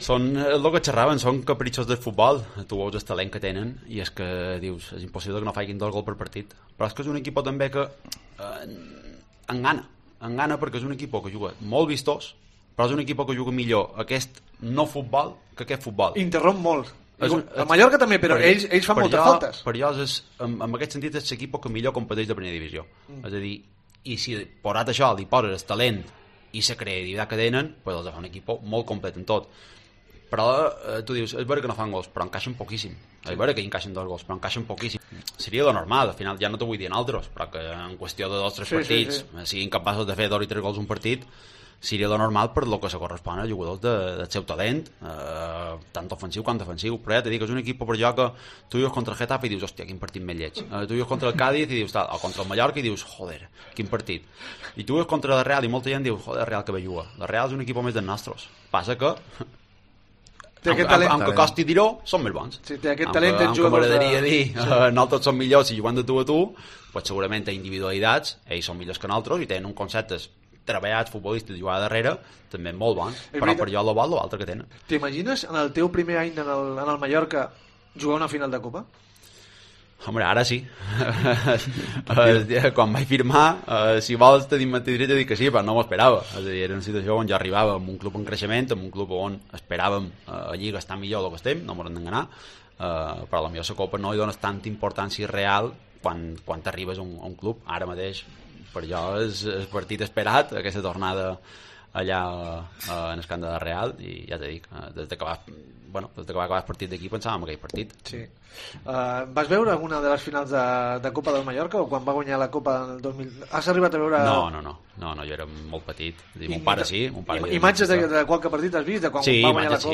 El centrocampista se mostraba ilusionado en Cope por esta semifinal ante la Real y habla del juego pragmático del rival